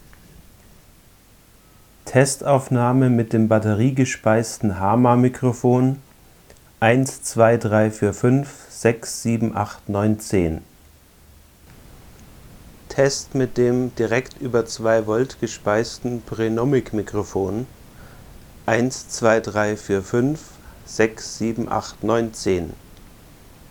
Ist eher schlechter als mein vorhandenes Hama (rauscht noch etwas mehr, hat einen geringeren Ausgangspegel und klingt für mein Empfinden etwas dumpfer).
Allerdings weiß ich nicht genau, wieviel Rauschen vom PreAmp des Recorders kommt und wieviel vom Mikro selber.
hama_pronomic_vergleich.mp3